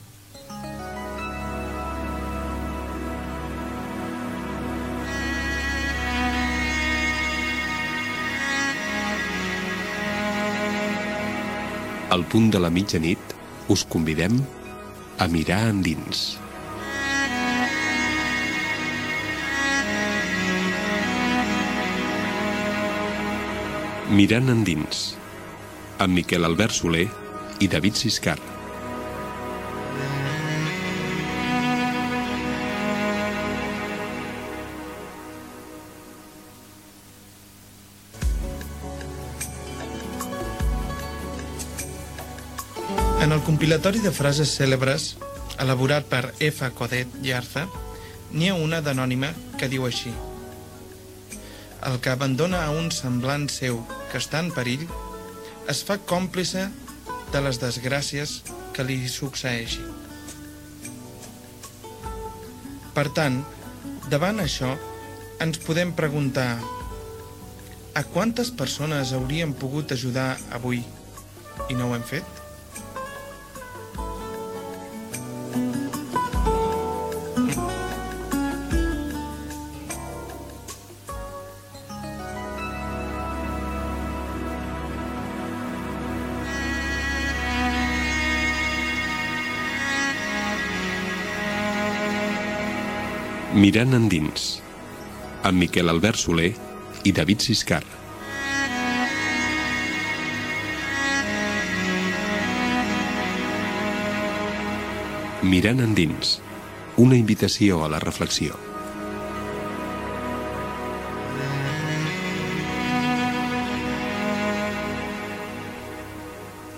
Careta del programa, frase sobre ajudar als altres, indicatiu